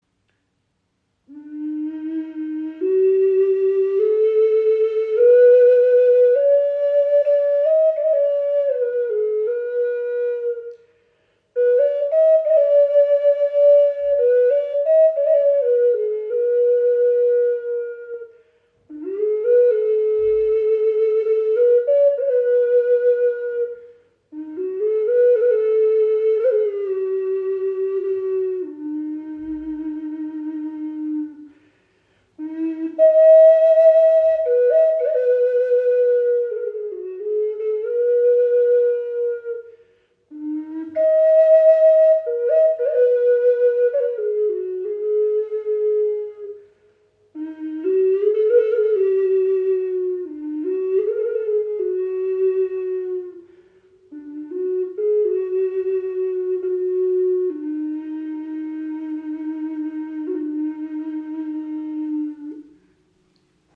Okarina aus Aststück | E4 in 432 Hz | Pentatonische Stimmung | ca. 18.5 cm
Klein, handlich, klangvoll – eine Okarina mit Seele
In pentatonischer E4 Moll Stimmung auf 432 Hz gestimmt, entfaltet sie einen warmen, klaren Klang, der Herz und Seele berührt.
Trotz ihrer handlichen Grösse erzeugt sie einen angenehm tiefen und warmen Klang – fast ebenbürtig zur nordamerikanischen Gebetsflöte.